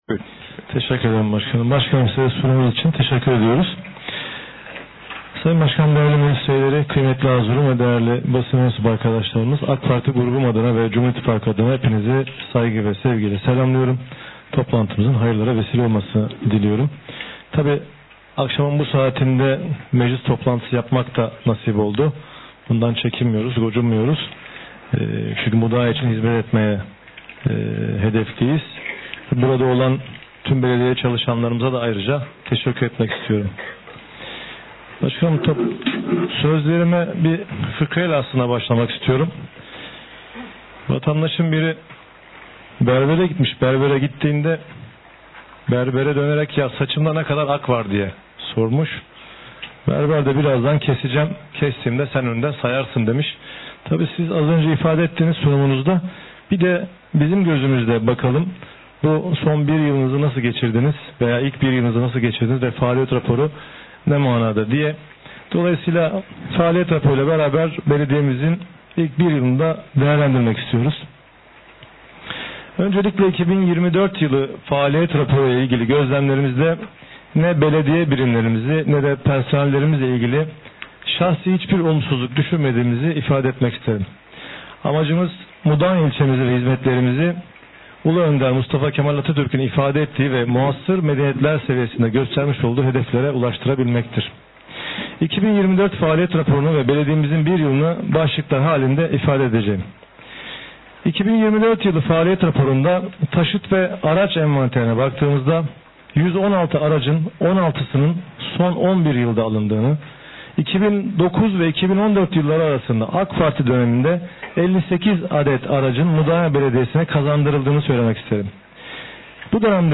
Mudanya Belediye Meclisi, 2025 yılı Nisan ayı ikinci oturumunu Dernekler Yerleşkesi Toplantı Salonu’nda gerçekleştirdi.
Ancak oylama öncesinde söz alan AK Parti Belediye Meclisi Grup Sözcüsü Yusuf Alper Ömeroğulları, faaliyet raporunu sert bir dille eleştirdi.